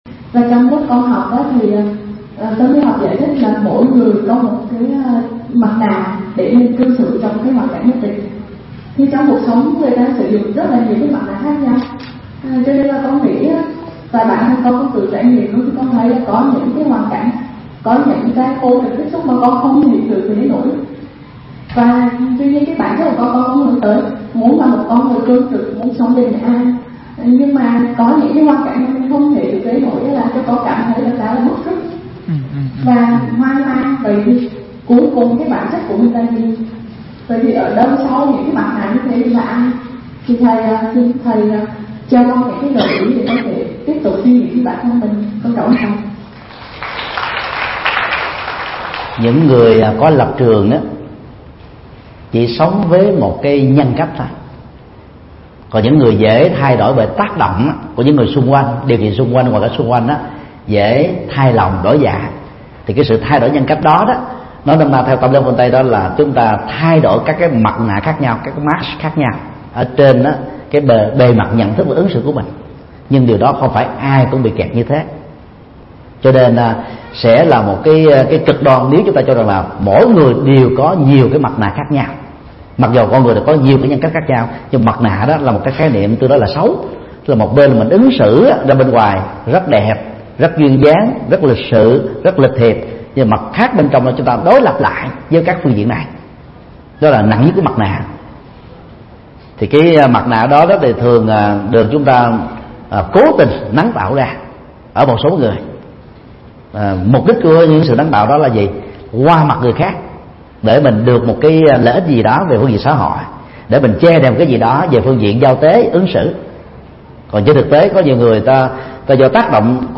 Vấn đáp: Mặt nạ nhân cách – Thích Nhật Từ